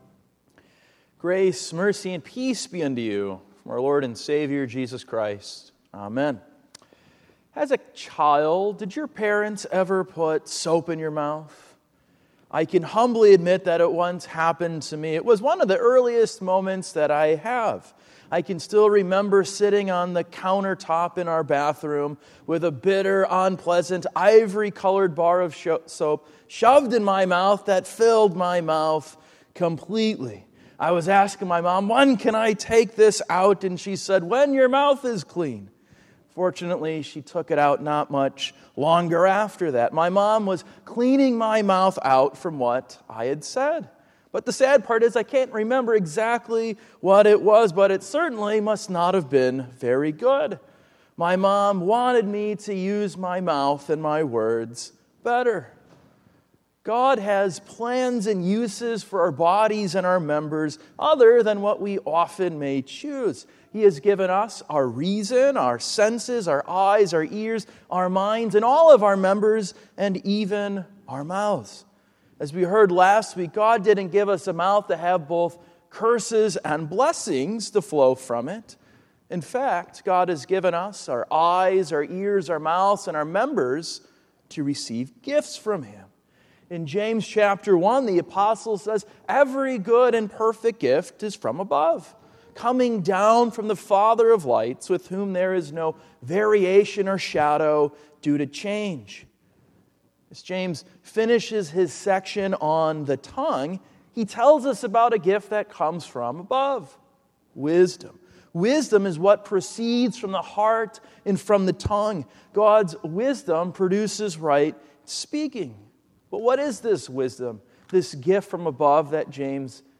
God Gives Gifts – James 3:13-18 – Emmaus Lutheran Church